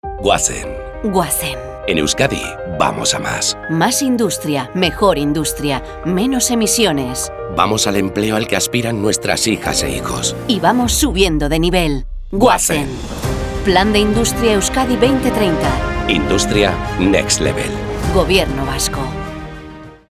Irrati-iragarkiak